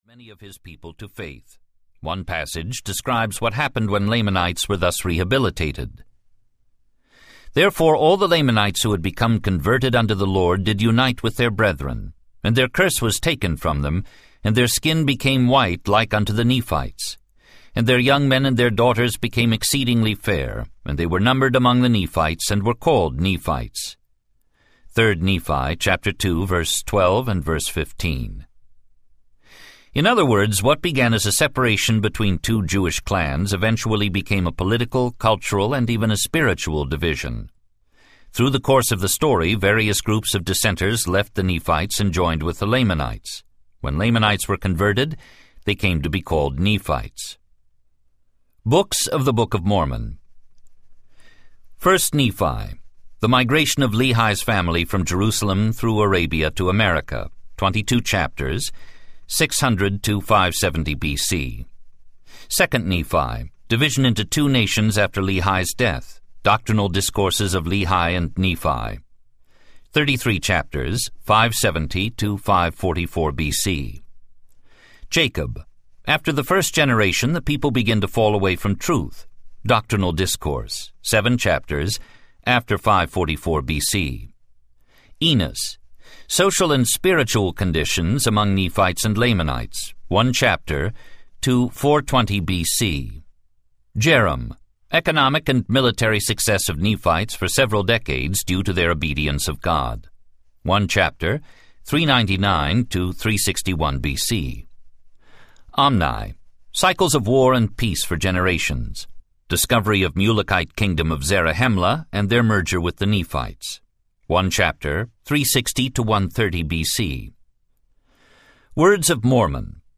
Understanding the Book of Mormon Audiobook
Narrator
2.3 Hrs. – Unabridged